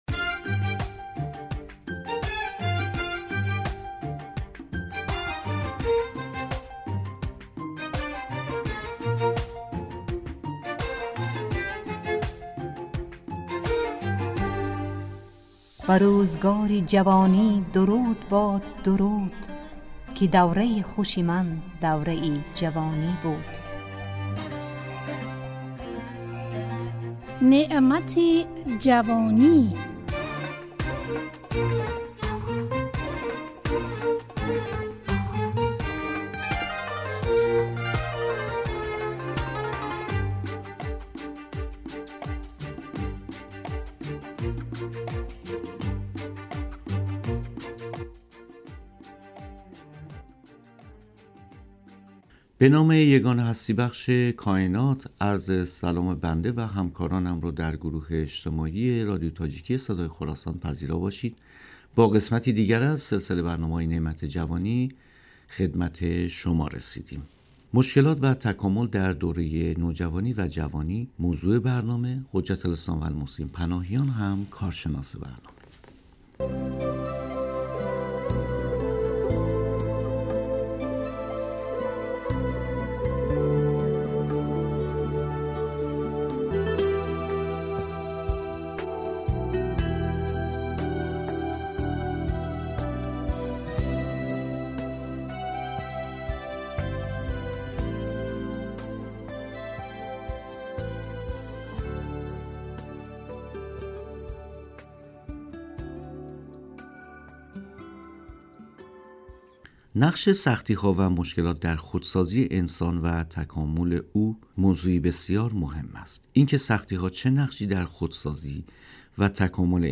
نعمت جوانی، برنامه ای از گروه اجتماعی رادیو تاجیکی صدای خراسان است که در آن، اهمیت این دوران باشکوه در زندگی انسان مورد بررسی قرار می گیرد.